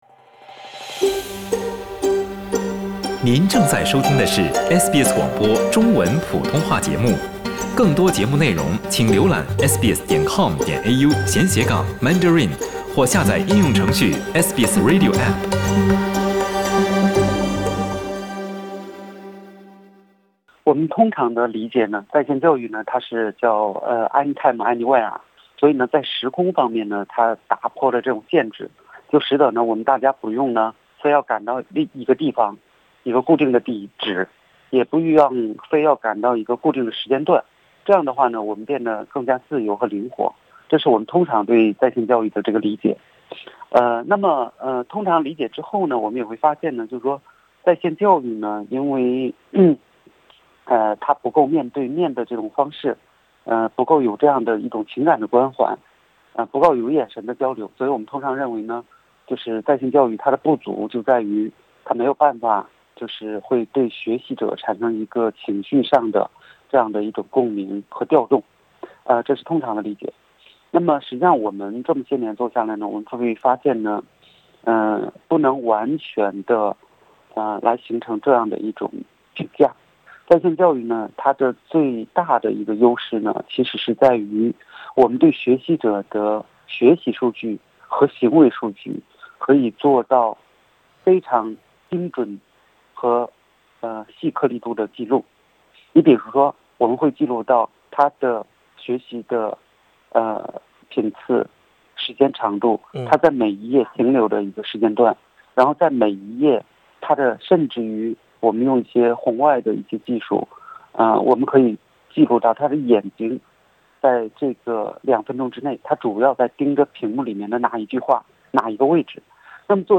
SBS Mandarin View Podcast Series